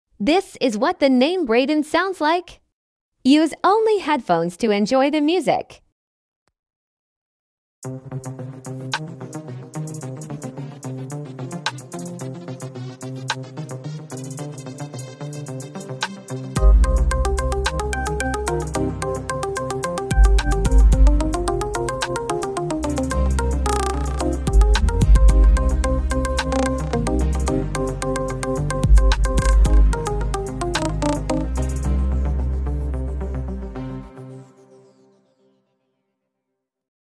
midi art